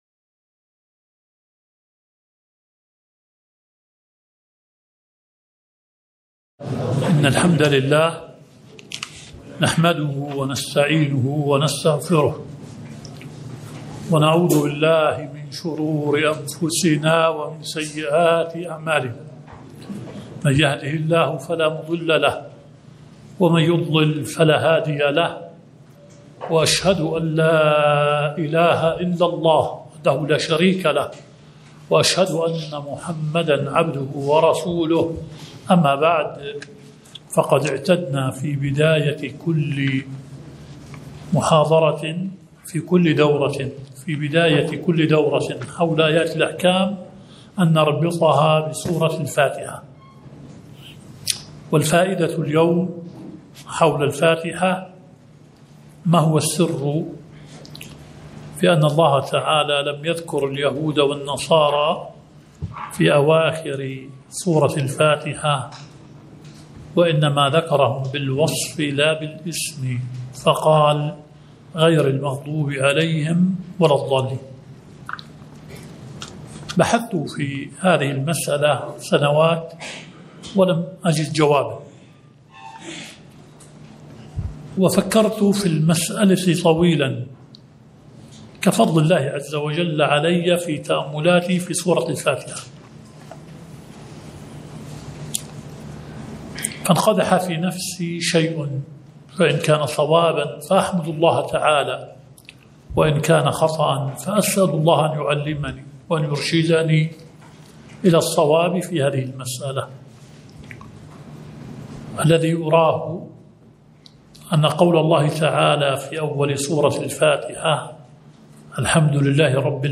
◀ المحاضرة الأولى 26/7/2052 ◀ رابط المحاضرة في الموقع
◀ نيل المرام في شرح آيات الأحكام الدورة العلمية السابعة و العشرون المقامة في مركز الإمام الألباني للدراسات والبحوث.